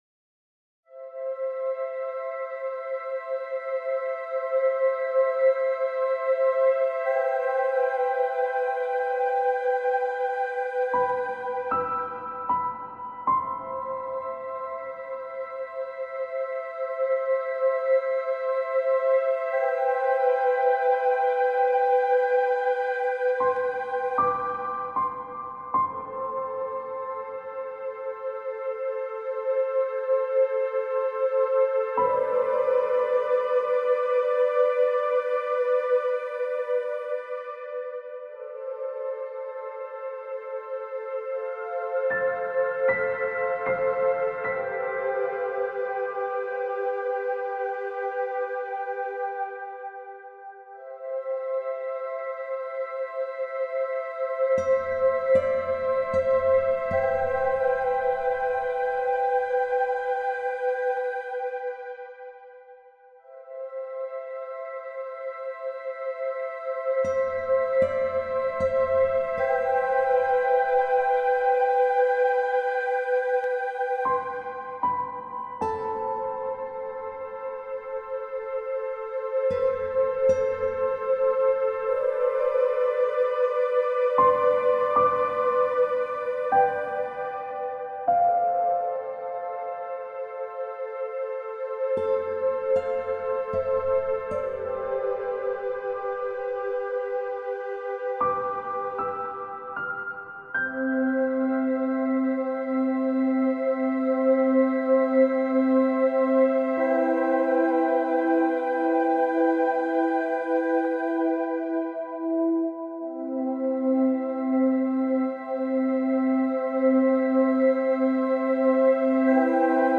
Stunde Klavier, Flöte & Gitarre in tiefer Atmosphäre